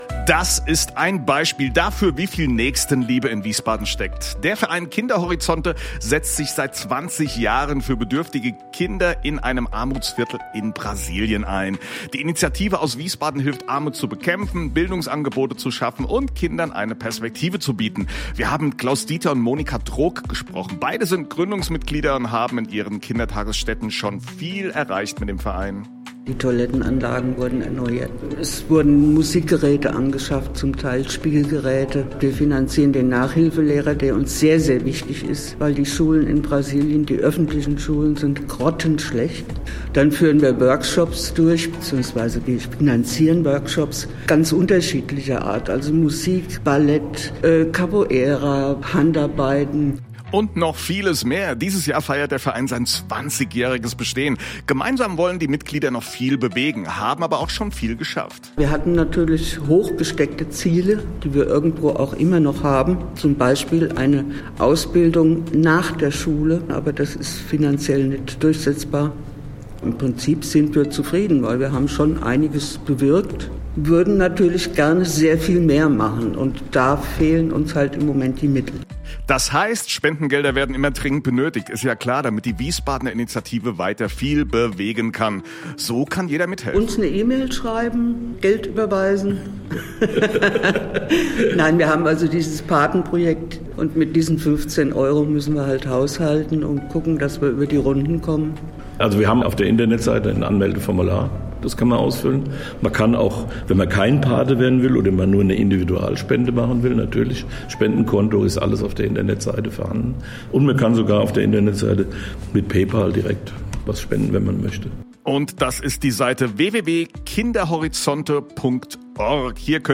Anlässlich des 20 jährigen Bestehens von Kinderhorizonte e.V. wurden unsere Wiesbadener Mitglieder zu einem Interview bei dem Radio Sender „Antenne Wiesbaden“ eingeladen.Hier der Mitschnitt, der uns freundlicherweise vom Sender zur Verfügung gestellt wurde.